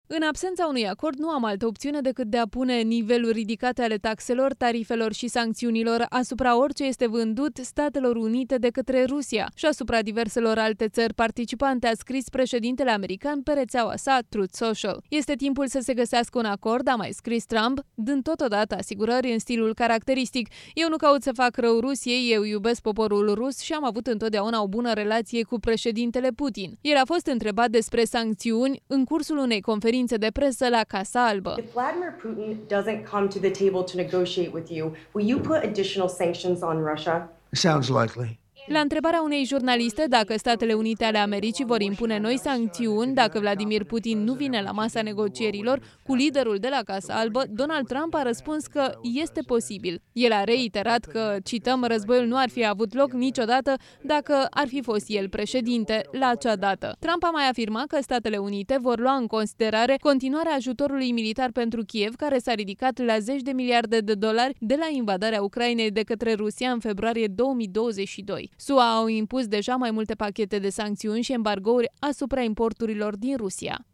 El a fost întrebat despre acest subiect în cursul unei conferinţe de presă la Casa Albă.
La întrebarea unei jurnaliste dacă SUA va impune noi sancțiuni dacă  Vladimir Putin nu vine la masa negocierilor cu liderul de la Casa Albă, Donald Trump a răspuns că este posibil.